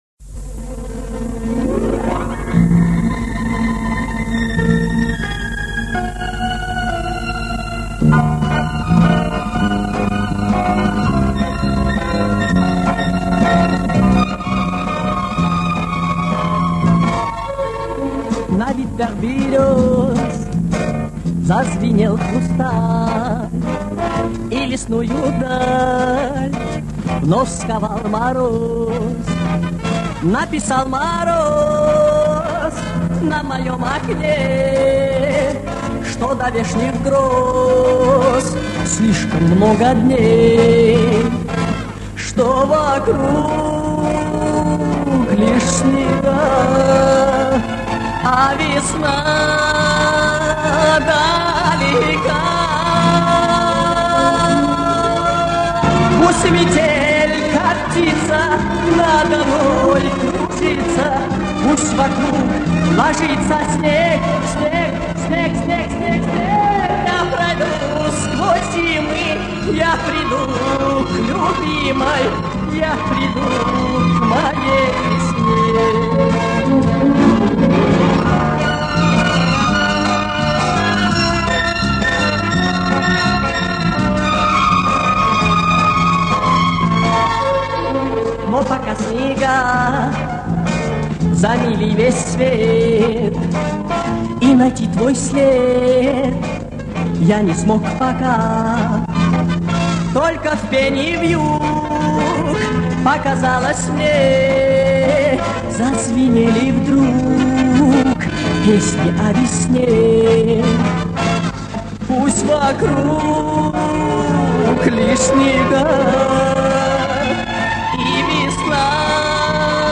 стало лучше, звучание четче и ровнее.